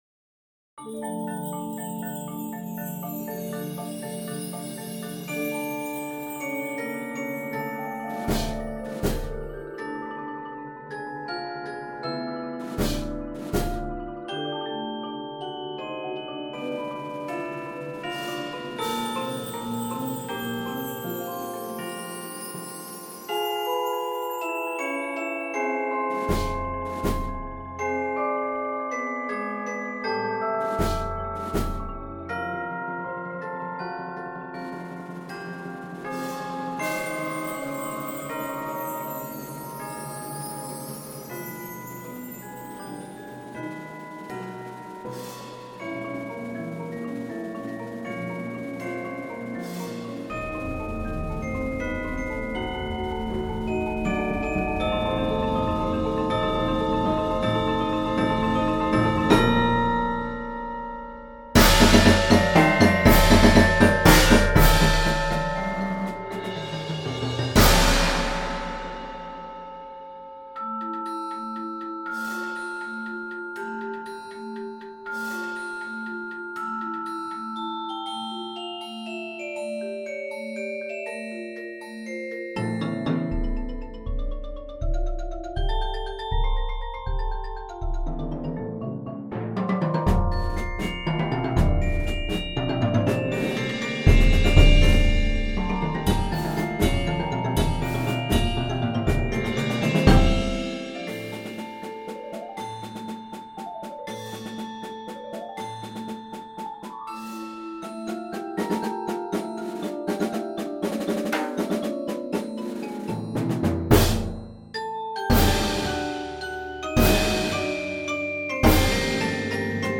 Voicing: Concert Percussion